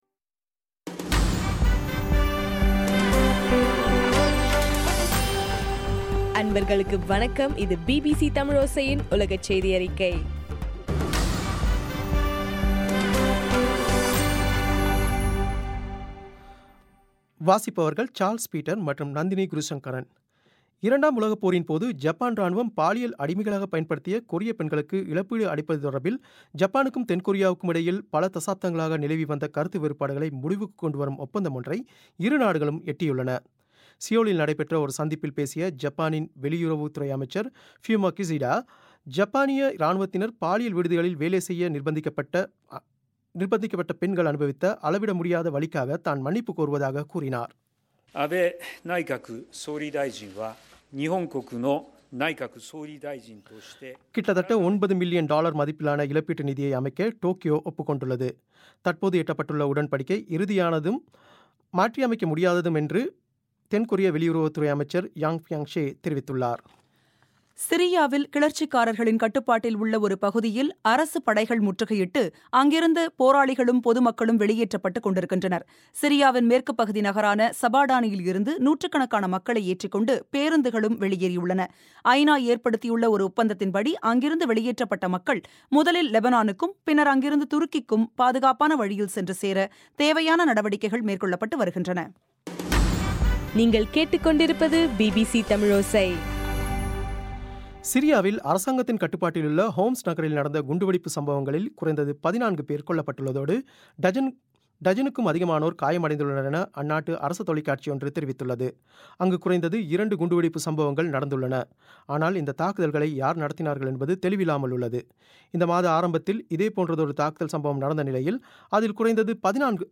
பிபிசி தமிழோசை உலகச் செய்தியறிக்கை- டிசம்பர் 28